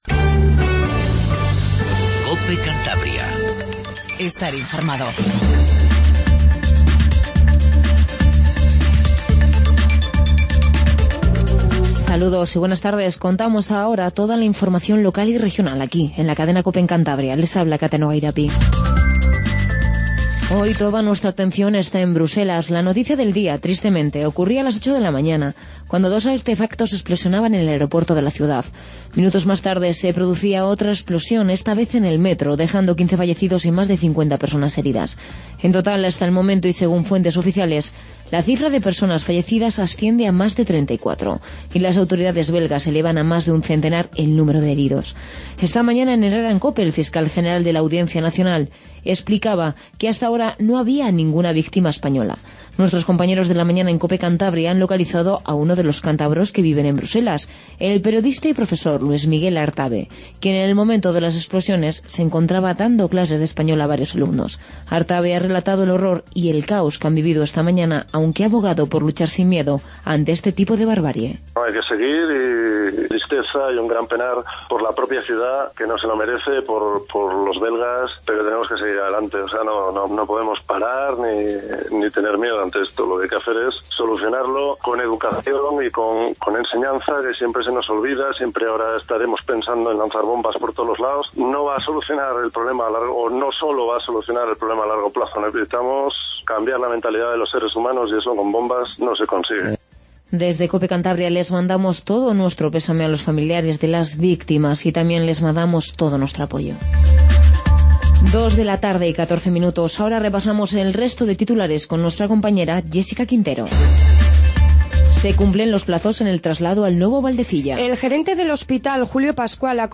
INFORMATIVO REGIONAL 14:10